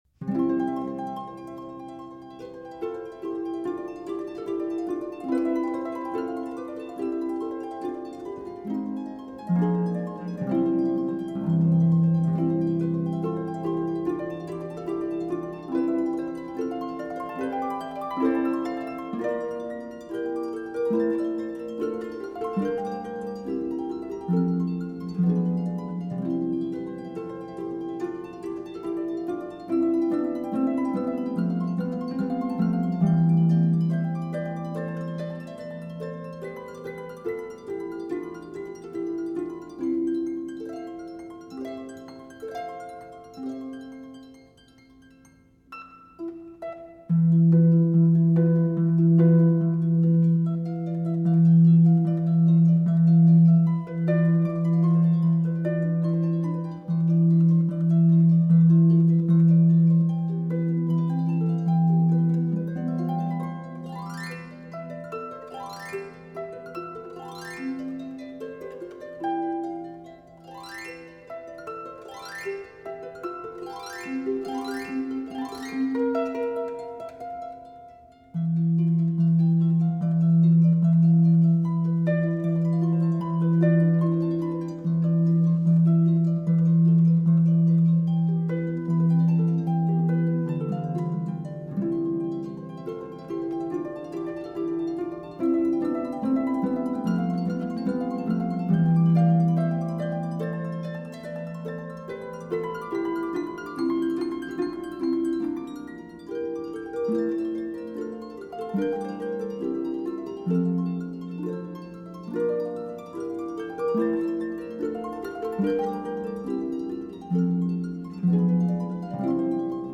满水分与泛音的银铃特质。
拨弄所呈现出的绮丽梦幻心境。